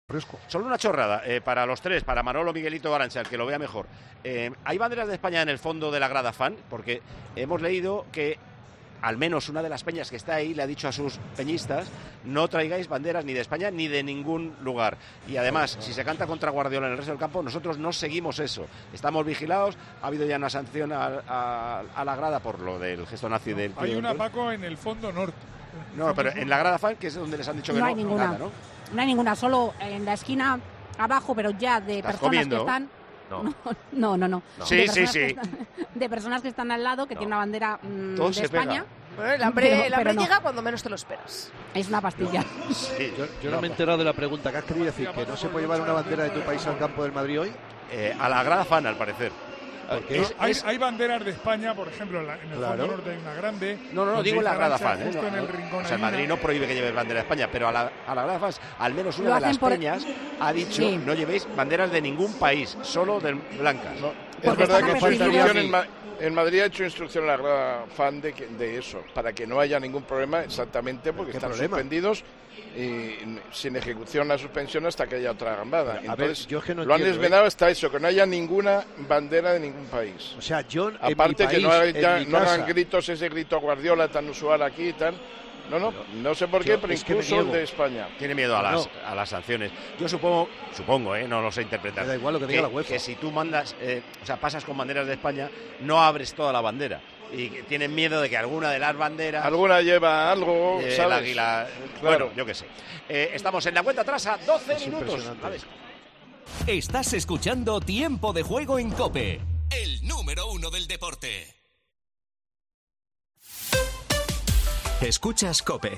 Paco González aborda con Manolo Lama, Alfredo Relaño y Poli Rincón la recomendación de no llevar banderas de España a la Grada Fans